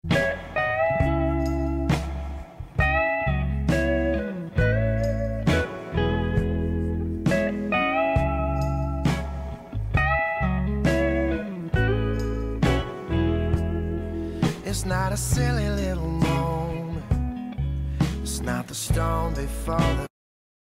playing guitar